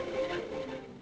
Added violin